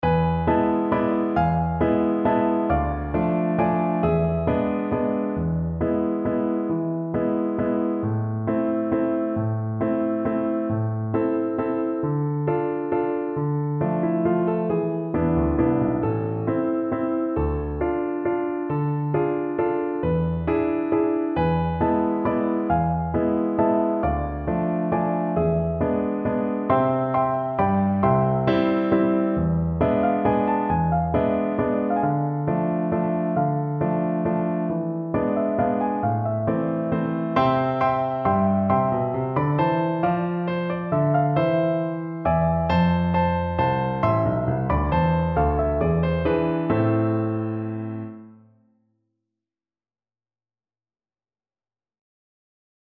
3/4 (View more 3/4 Music)
One in a bar .=c.45
world (View more world French Horn Music)
Israeli